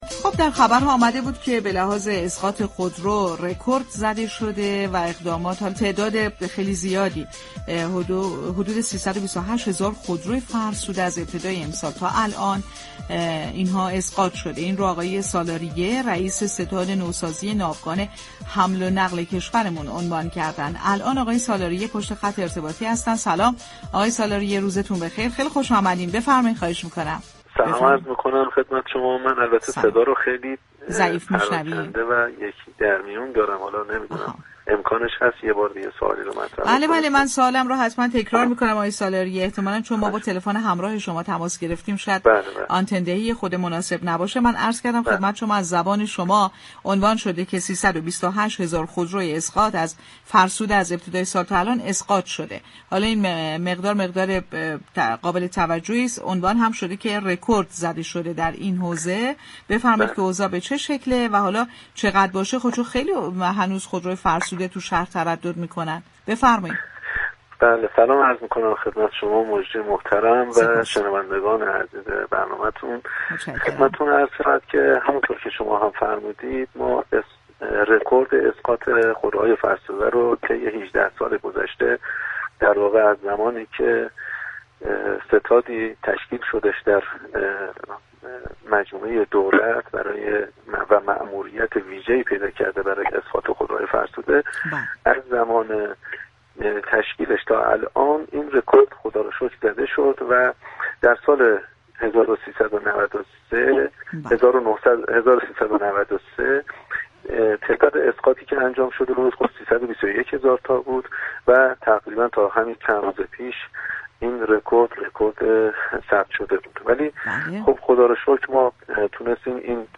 به گزارش پایگاه اطلاع رسانی رادیو تهران، مهران سالاریه رئیس ستاد نوسازی ناوگان حمل و نقل در گفت و گو با «بازار تهران» درباره آخرین آمار اسقاط خودروهای فرسوده اظهار داشت: ركورد اسقاط خودروهای فرسوده طی 18 سال گذشته (از زمان تشكیل ستاد نوسازی ناوگان حمل و نقل تا كنون) شكسته شد.